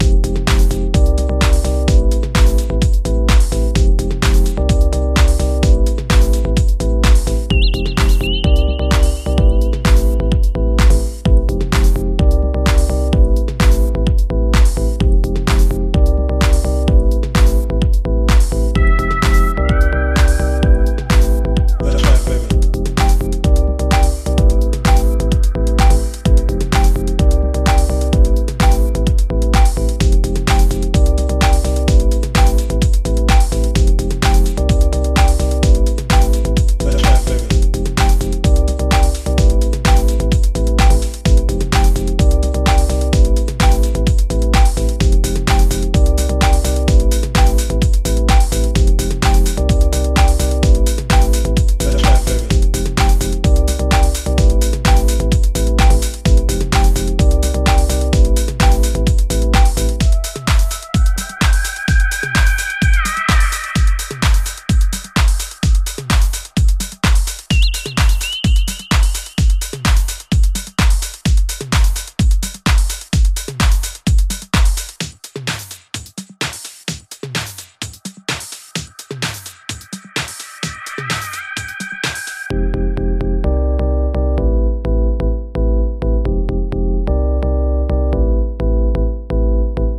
多彩なグルーヴやオーガニックでタッチでオーセンティックなディープ・ハウスの魅力を醸し、モダンなセンスで洗練された意欲作。